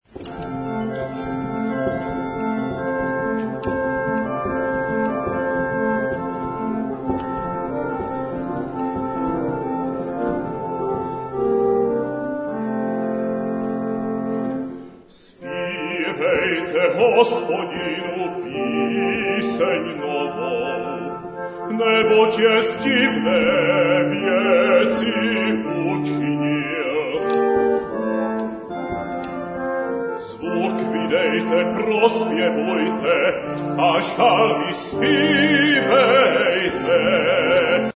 Zde jsou uvedeny ukázky skladeb, které zazněli na koncertě.